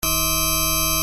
Ponadto dźwięki te posiadają także tę samą wysokość.